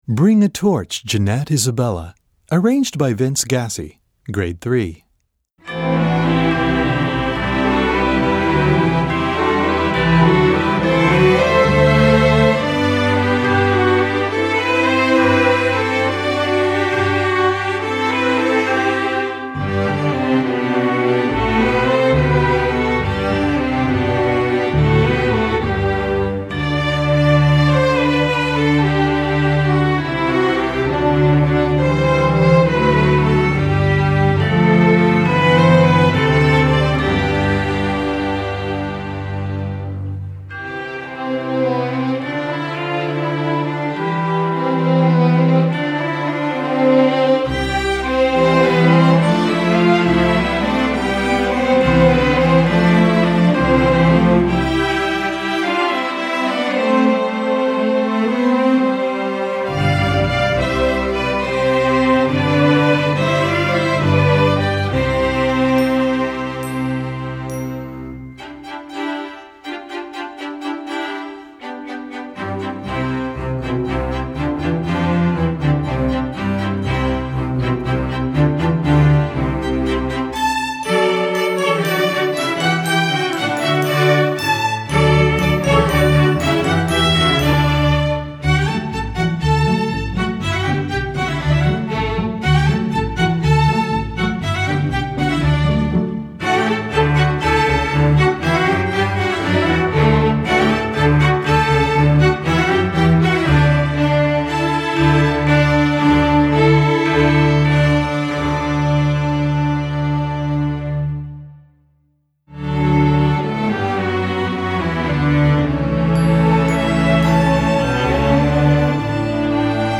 Gattung: Streichorchester
Besetzung: Streichorchester
Nothing says Noel better than a 16th century courtly dance.